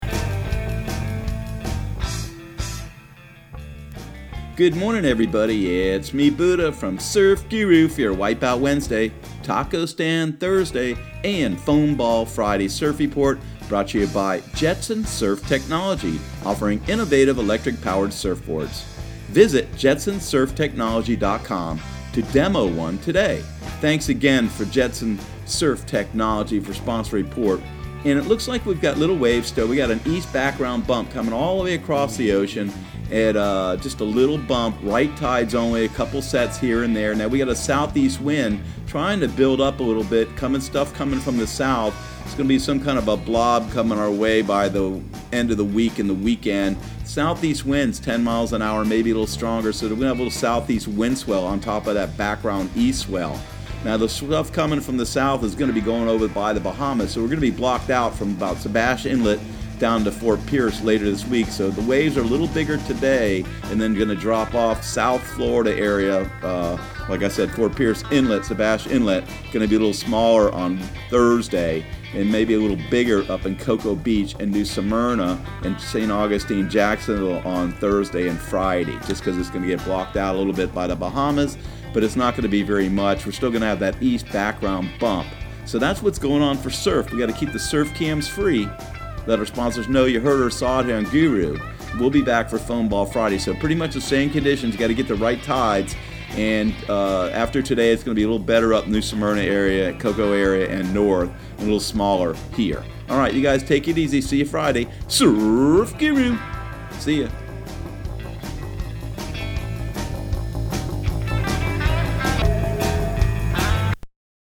Surf Guru Surf Report and Forecast 07/31/2019 Audio surf report and surf forecast on July 31 for Central Florida and the Southeast.